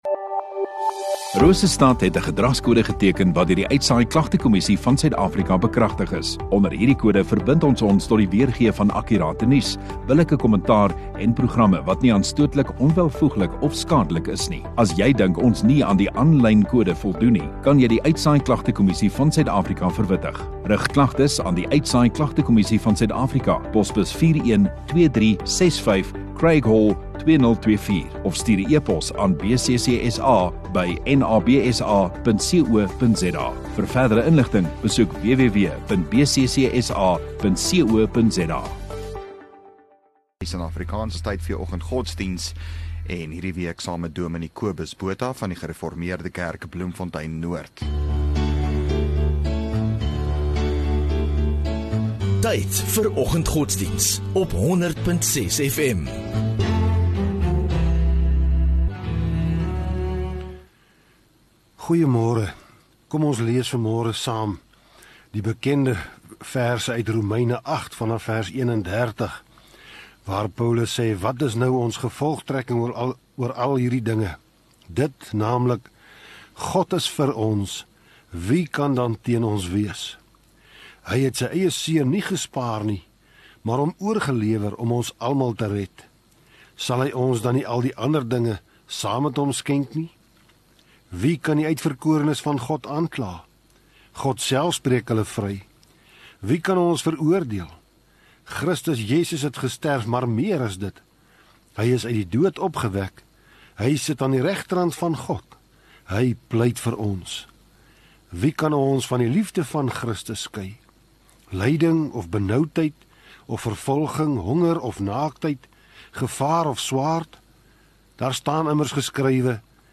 27 Jun Vrydag Oggenddiens